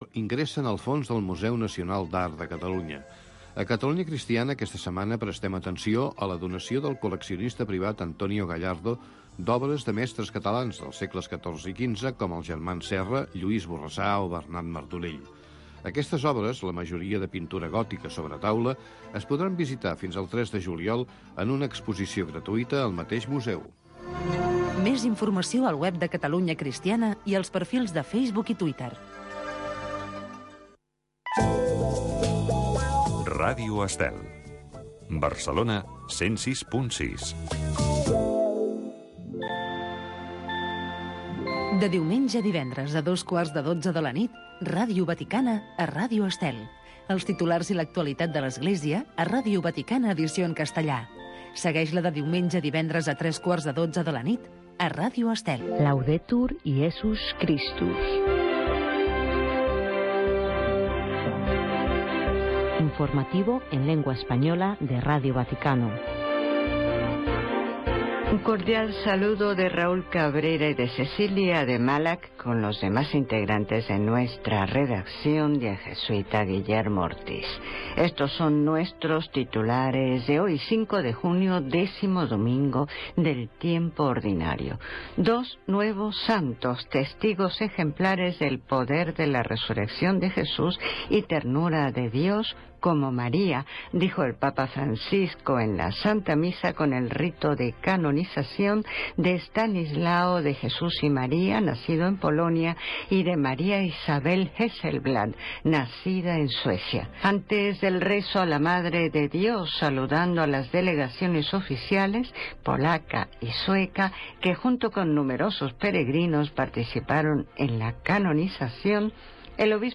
Radio Vaticana. L'informatiu en castellà de Ràdio Vaticana. Tota l'activitat del pontífex, com també totes aquelles notícies de Roma.